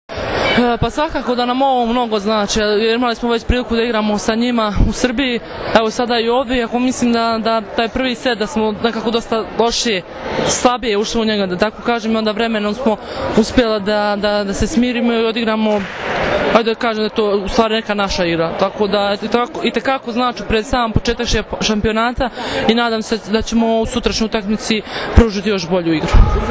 IZJAVA BRANKICE MIHAJLOVIĆ